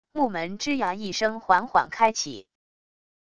木门吱呀一声缓缓开启wav音频